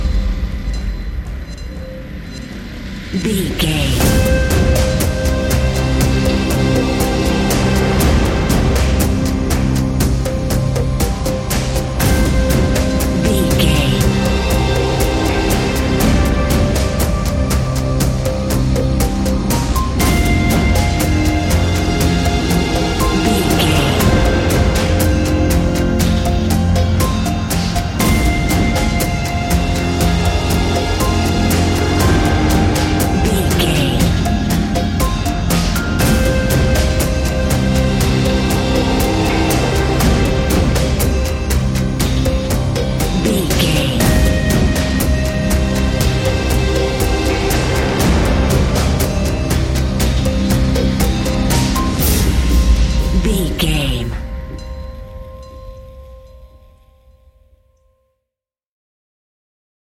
Thriller
Ionian/Major
dark ambient
EBM
synths
Krautrock